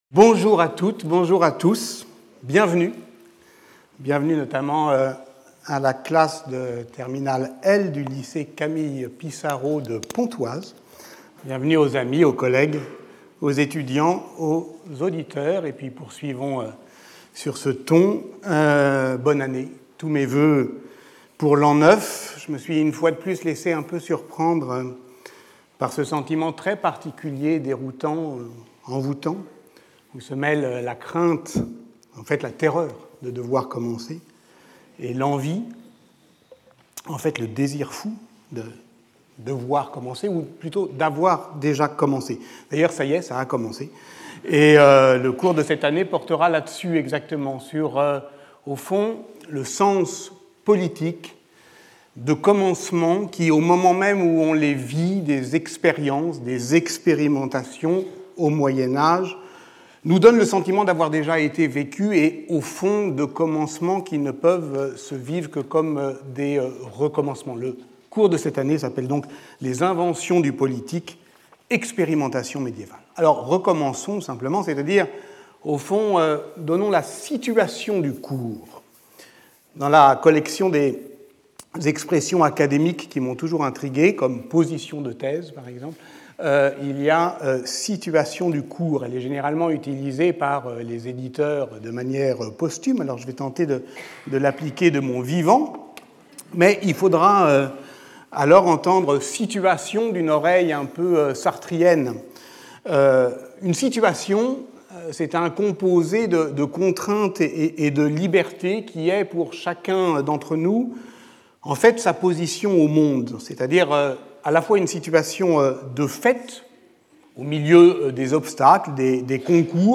Skip youtube video player Listen to audio Download audio Audio recording Abstract The lecture begins with an evocation of its " situation " in the course of the lectures (courses and seminars) since 2016 by returning to the euristic value of the notion of experience.